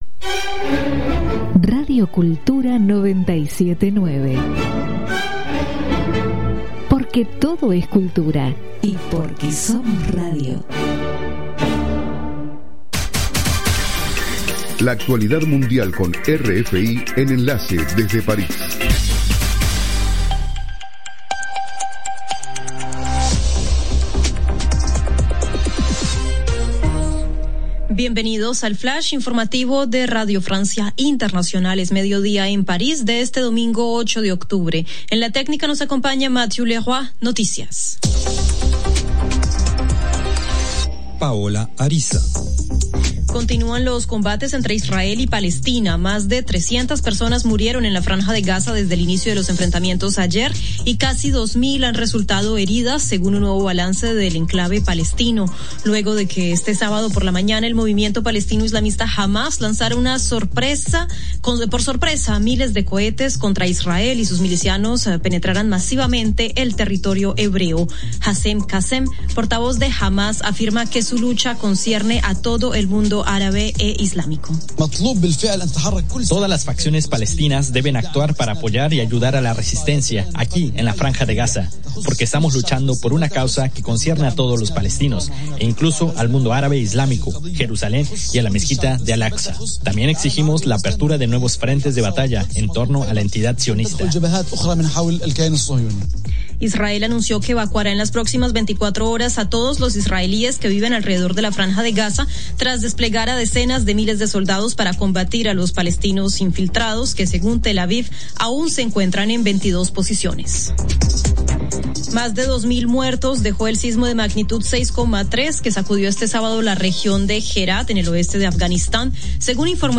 Programa: RFI - Noticiero de las 07:00 Hs.